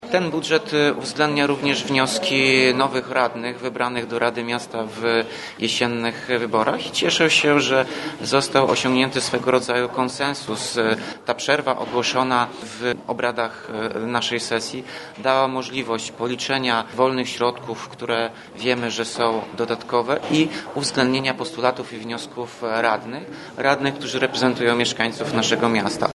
– Znaleźliśmy większe niż zakładaliśmy wolne środki z 2018 roku – wyjaśnił po głosowaniu Tomasz Andrukiewicz, prezydent Ełku.